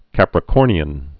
(kăprĭ-kôrnē-ən)